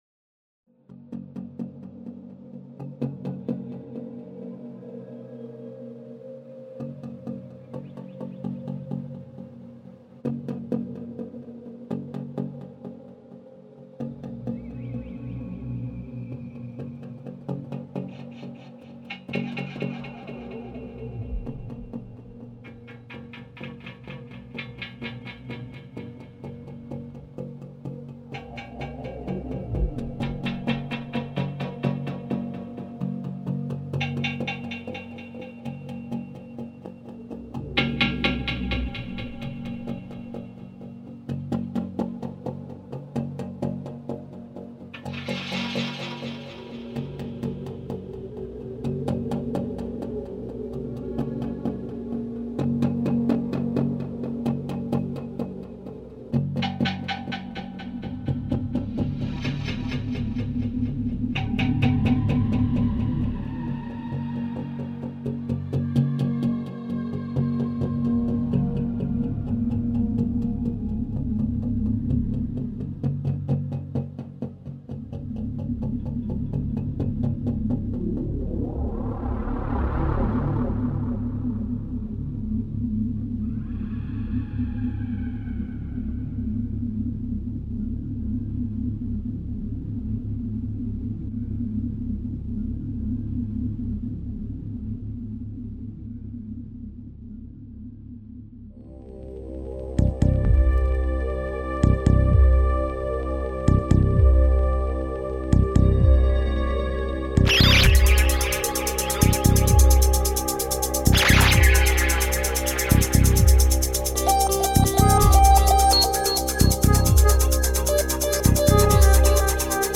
dawn-at-vara-1.mp3